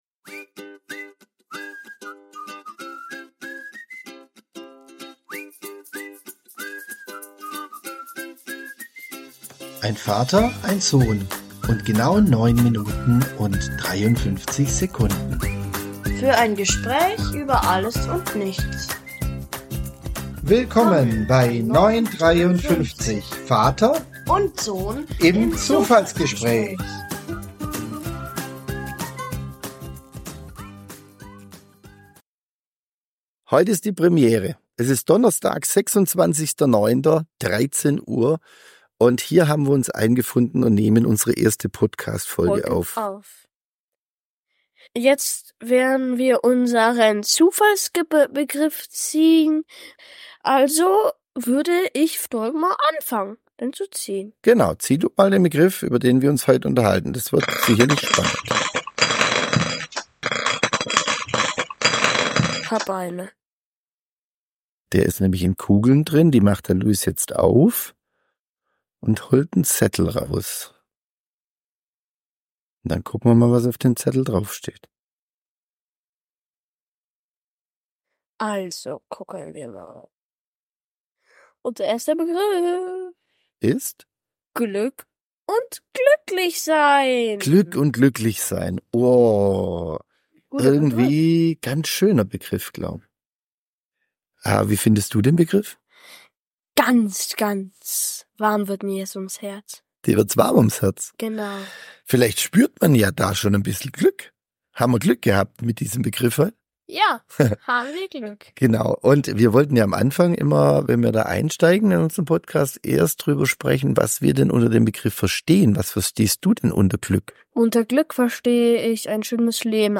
Wir sprechen darüber, was für uns Glück oder glücklichsein bedeutet, tauschen uns über glückliche Momente aus, philosophieren, ob Glück leise oder laut ist und sprechen über die kleinen Freuden des Alltags. Ein humorvolles und tiefgründiges Gespräch zwischen Generationen, das Euch hoffentlich zum Nachdenken und Lächeln anregt.